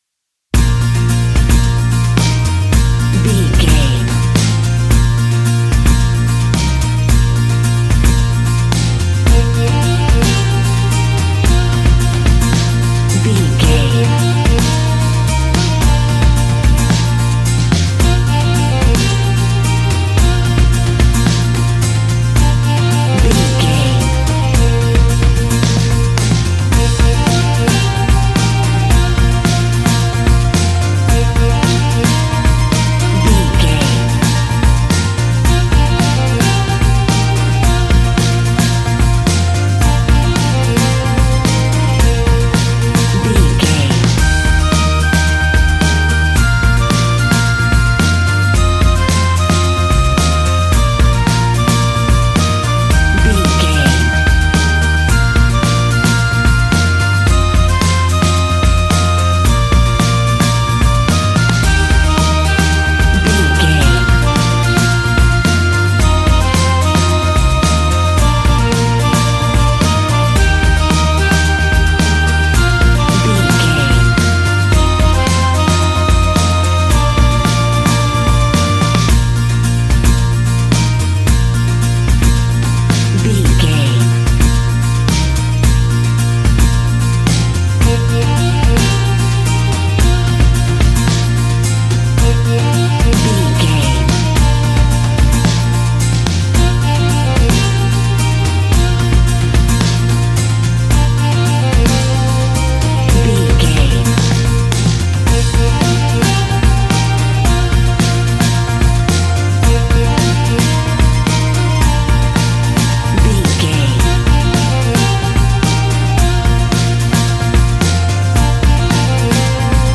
Uplifting
Ionian/Major
E♭
earthy
acoustic guitar
mandolin
ukulele
lapsteel
drums
double bass
accordion